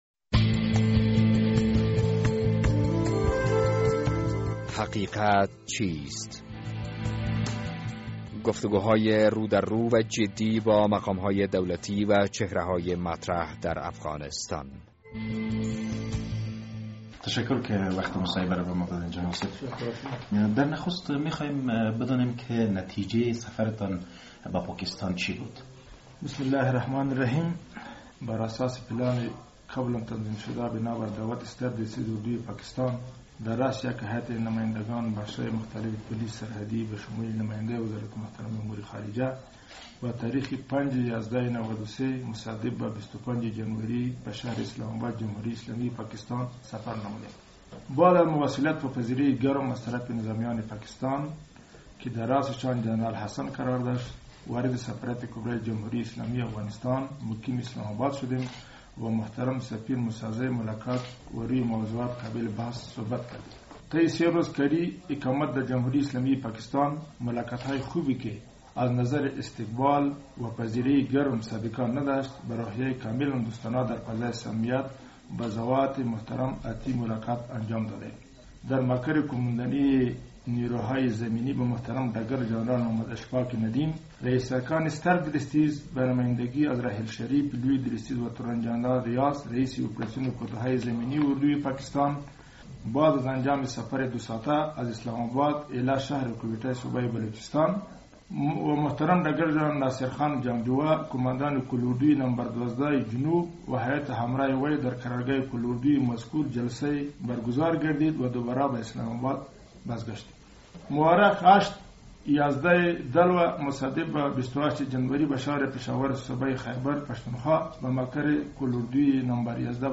در این برنامه "حقیقت چیست؟" با شفیق فضلی قوماندان پولیس سرحدی افغانستان گفتگو کرده ایم.